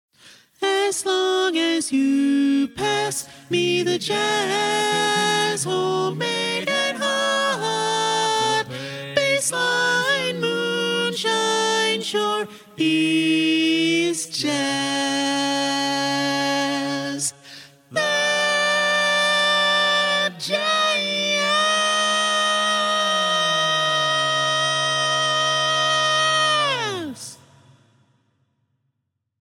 Key written in: D♭ Major
Type: Barbershop